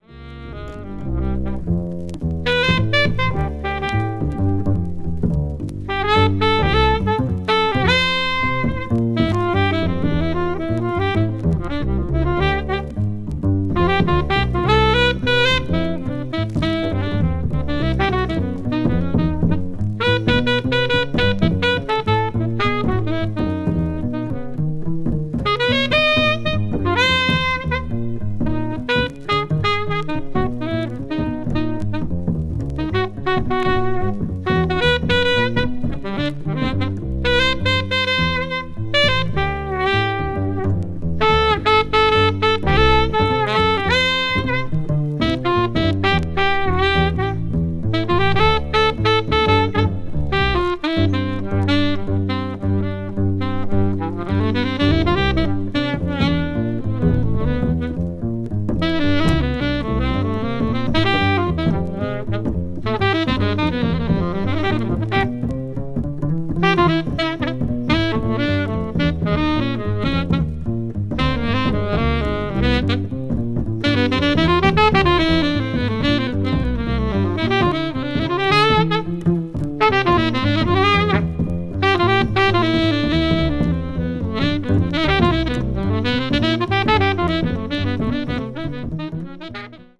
Moog Synthesizer